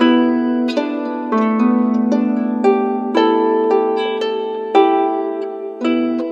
Harp03_114_G.wav